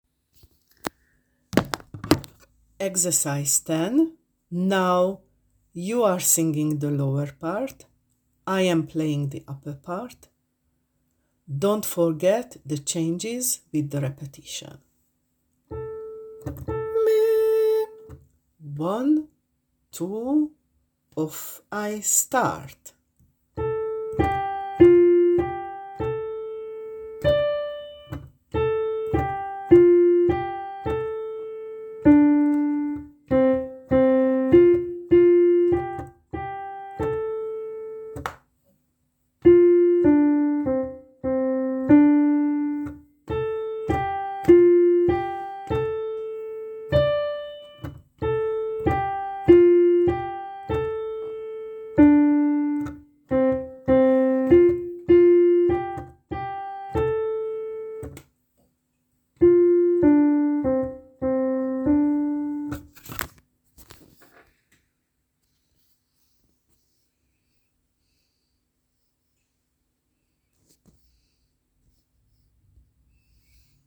Part work, polyphony: